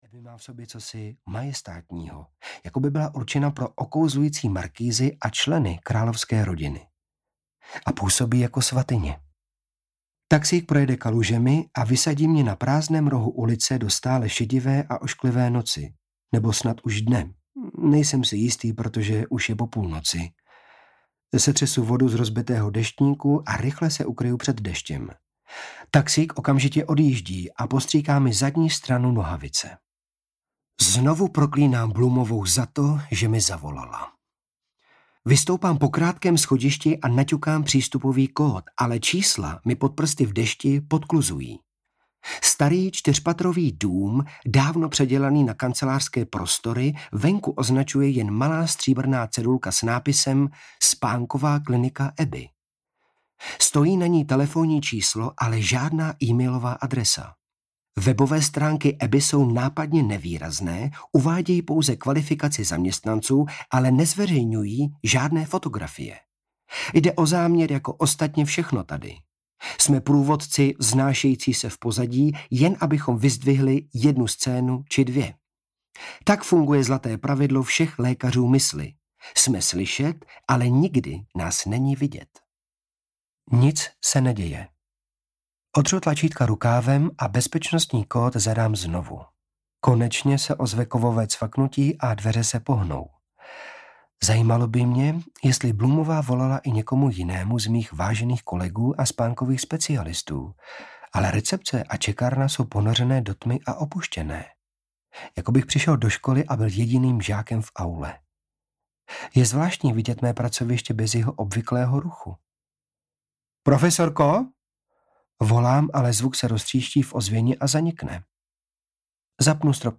Anna O. audiokniha
Ukázka z knihy